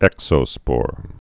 (ĕksō-spôr)